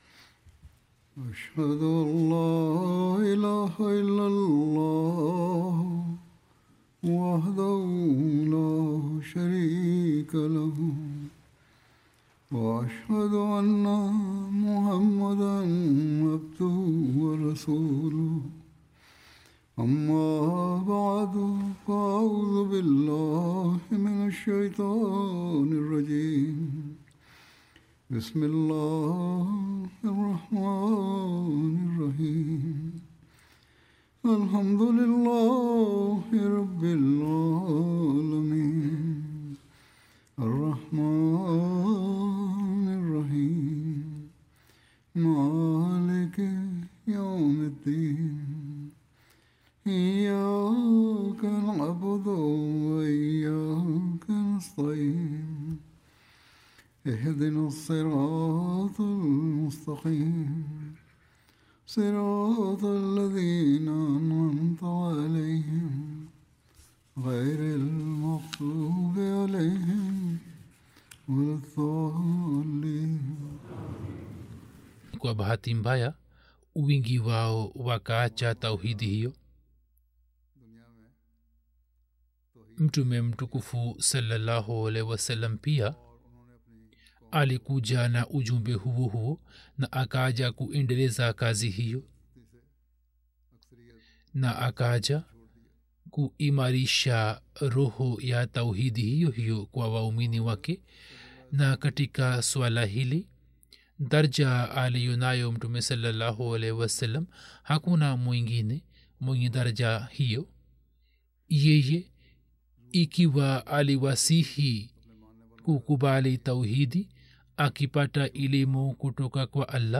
27 February 2026 The Prophet(sa): The Perfect Herald of Divine Unity Swahili Friday Sermon by Head of Ahmadiyya Muslim Community 50 min About Swahili translation of Friday Sermon delivered by Khalifa-tul-Masih on February 27th, 2026 (audio)